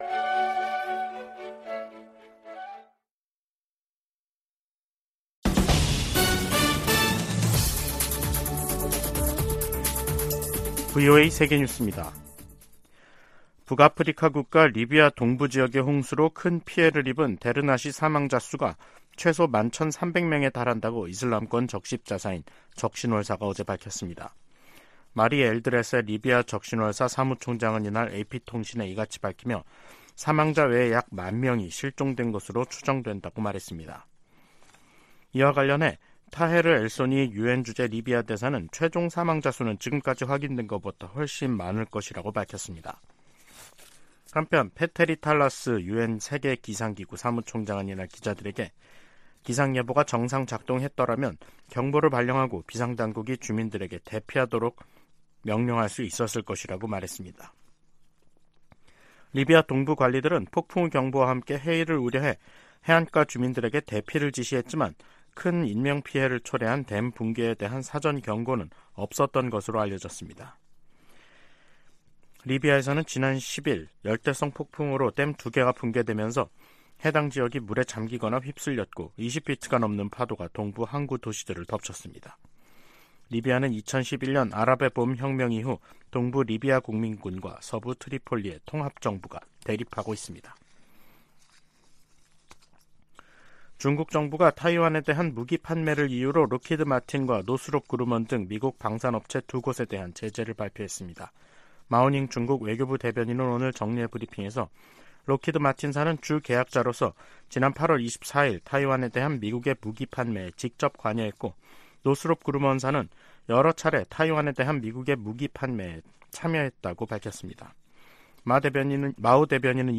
VOA 한국어 간판 뉴스 프로그램 '뉴스 투데이', 2023년 9월 15일 2부 방송입니다. 미국과 한국은 유엔 안보리 결의에 부합하지 않는 북한과 러시아 간 협력에 강력한 우려를 표명한다고 밝혔습니다. 미 국방부는 북한과 러시아 간 추가 무기 거래 가능성과 관련, 민간인 학살에 사용되는 무기를 제공해선 안 된다고 거듭 강조했습니다. 미국 정부가 한국에 대한 50억 달러 상당 F-35 스텔스 전투기 25대 판매를 승인했습니다.